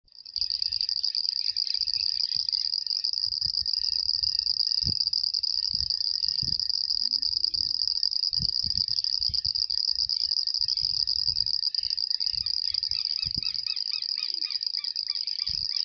Pseudopaludicola falcipes
Class: Amphibia
Spanish Name: Ranita Enana Común
Sex: Both
Location or protected area: Saladillo
Condition: Wild
Certainty: Recorded vocal